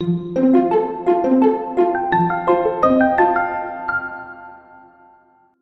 Позитивный настрой